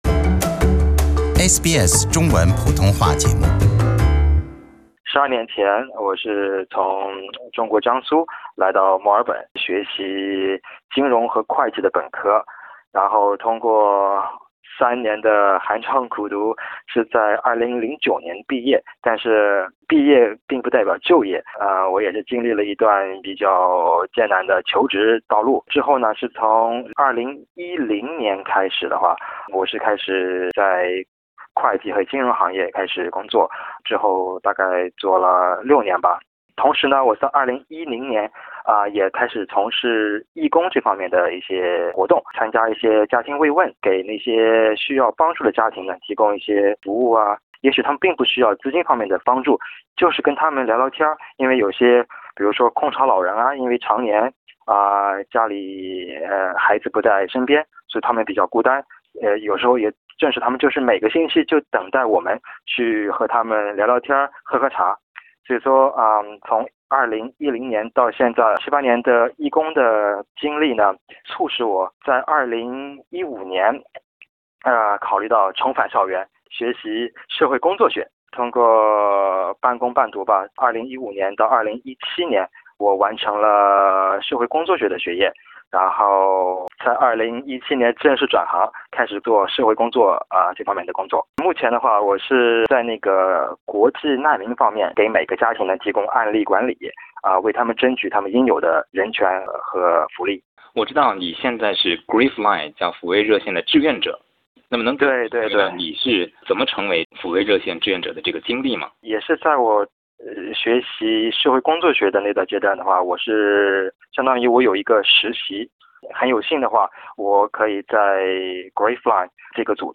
一位來自GriefLine的普通話服務志願者給SBS普通話節目講述了其葠加志願服務的經厤。